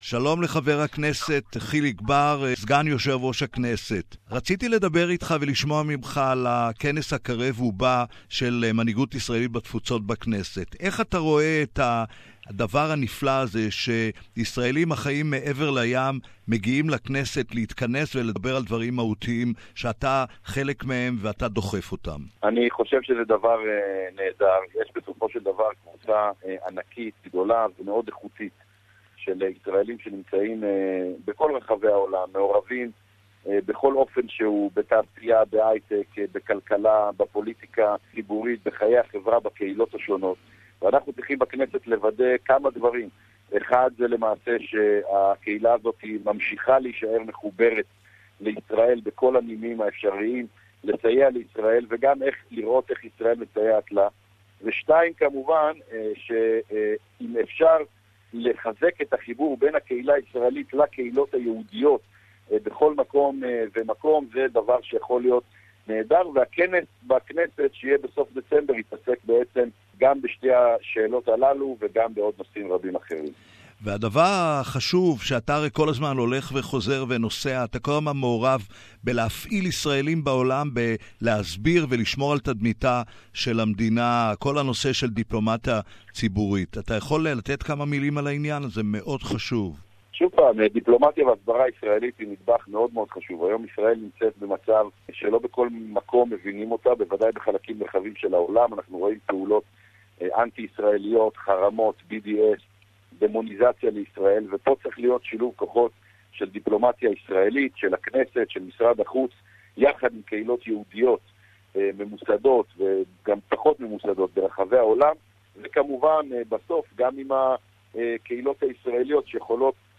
MK Hilik Bar, Deputy Knesset Speaker Source: Knesset